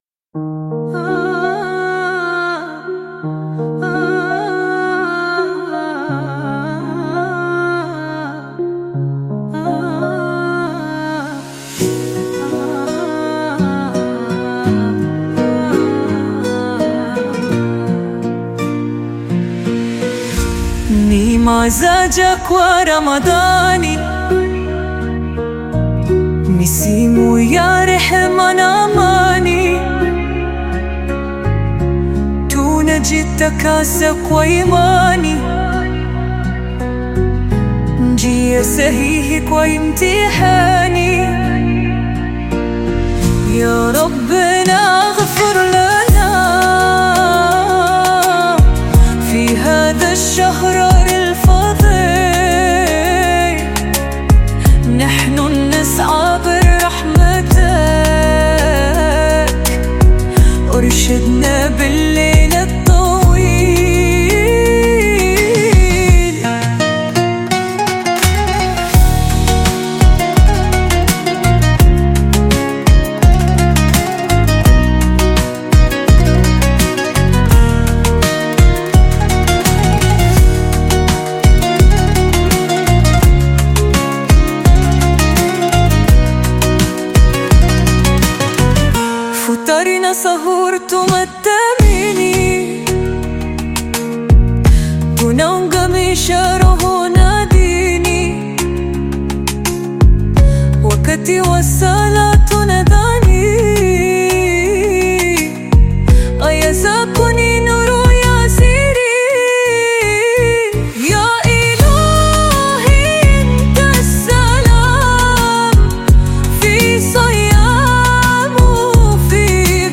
Qaswida You may also like